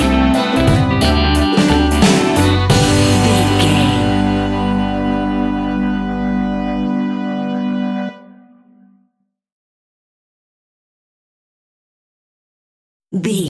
Hot summer reggae music from Barbados!
Ionian/Major
laid back
chilled
off beat
drums
skank guitar
hammond organ
percussion
horns